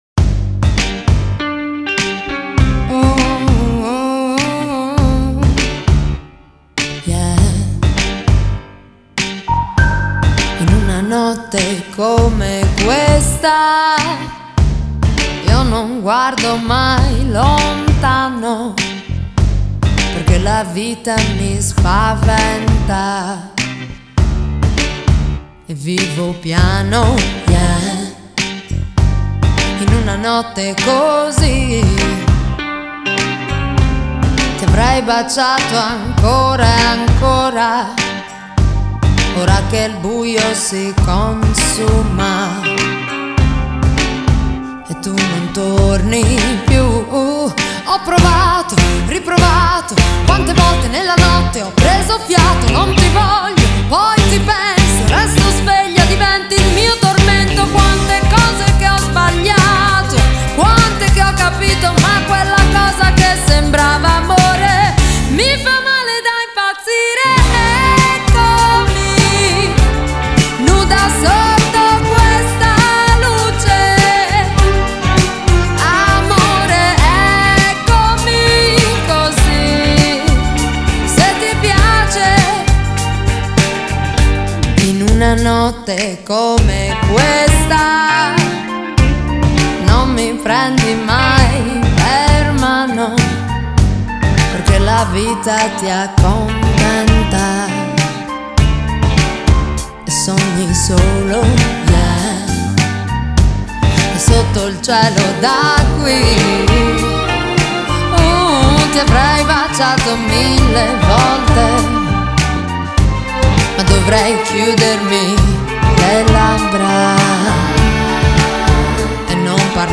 Genre: Pop, Soul